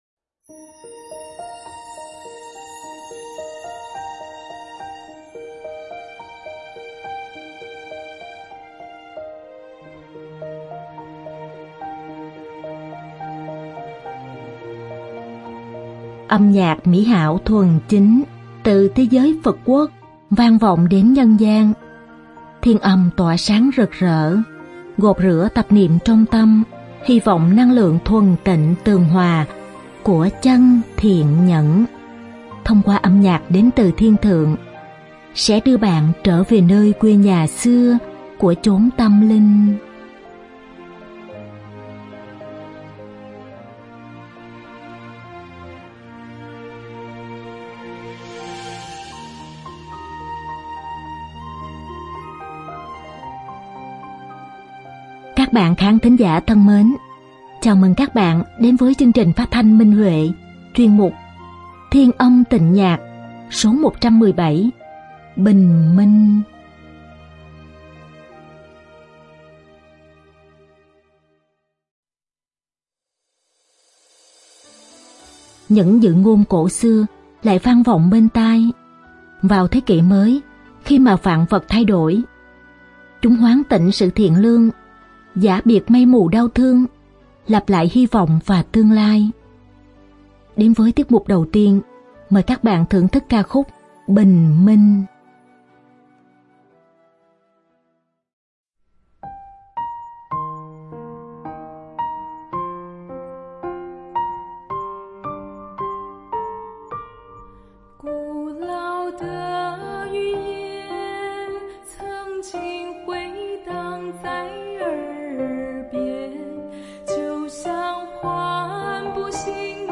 Ca khúc
Nhạc phẩm
Diễn tấu dương cầm